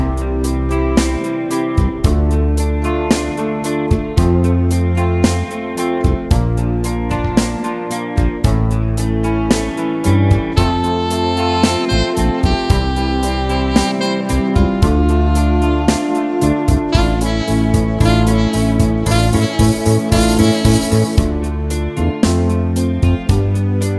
Short Version Pop (1980s) 3:21 Buy £1.50